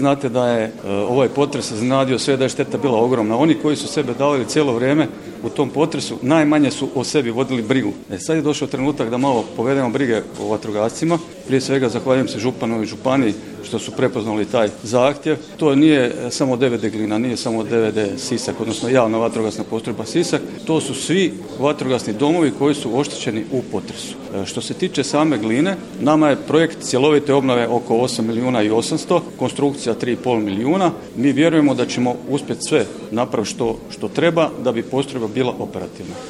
U petak, 16. prosinca 2022. godine, u prostoru DVD-a Desna Martinska Ves predstavljen je Javni poziv za dodjelu bespovratnih sredstava za obnovu objekata koje koriste javne vatrogasne postrojbe i dobrovoljna vatrogasna društva na potresom pogođenom području.